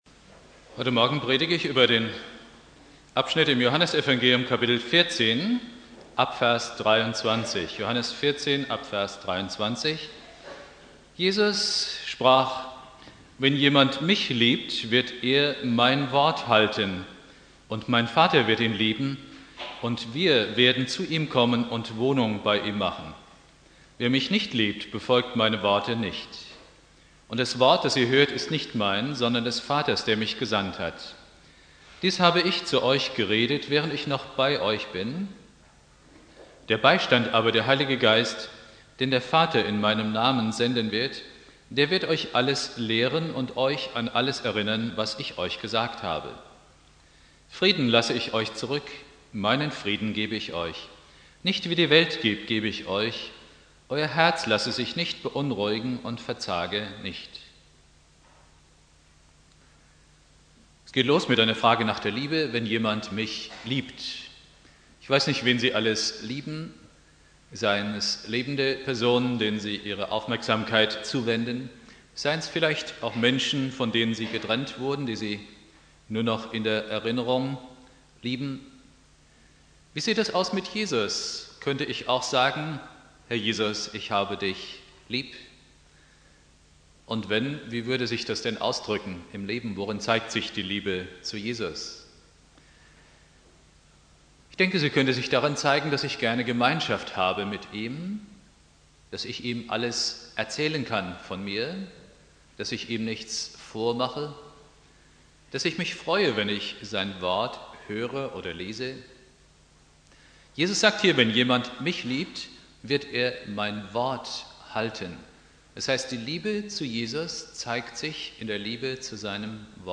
Predigt
Pfingstsonntag Prediger